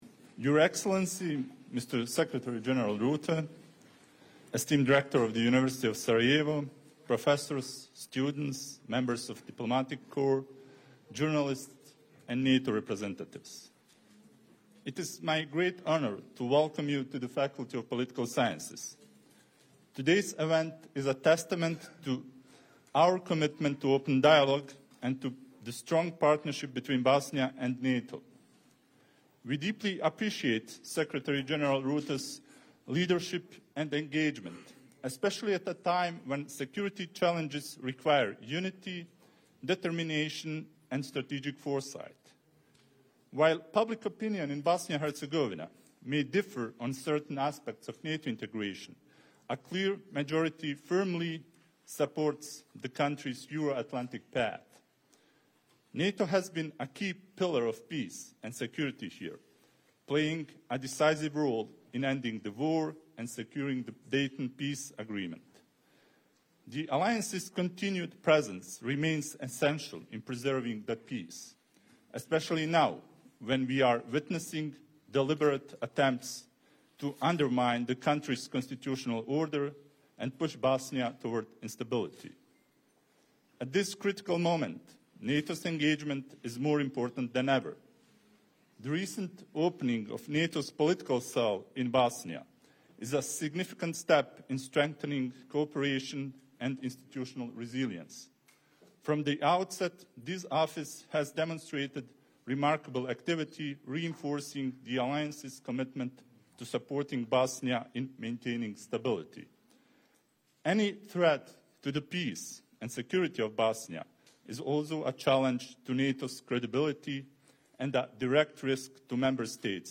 Speech
by NATO Secretary General Mark Rutte at the University of Sarajevo followed by an exchange of views with students